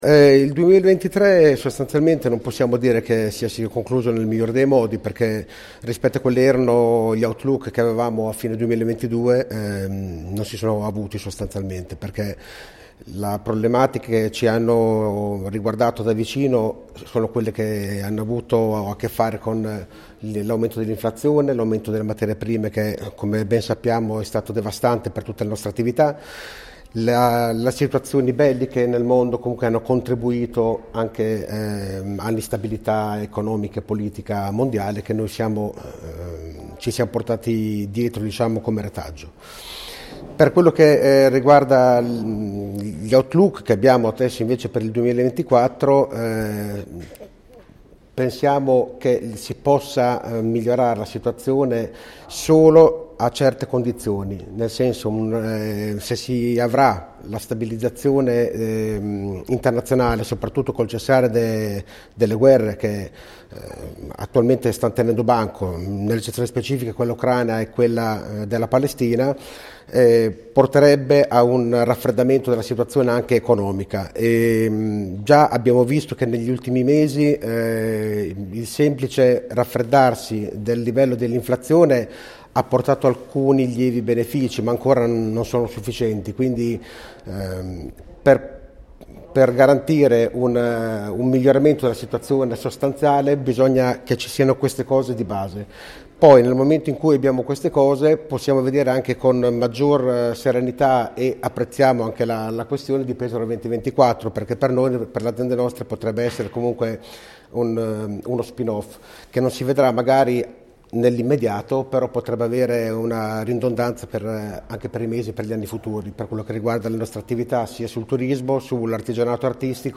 Conferenza-inizio-anno-cna.mp3